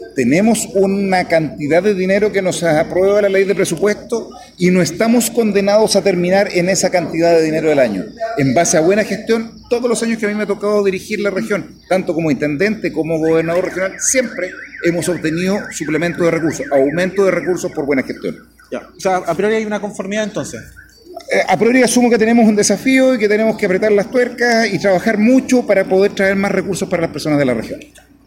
Al respecto, el gobernador Regional, Rodrigo Díaz, dijo que todavía se pueden gestionar más recursos para la zona.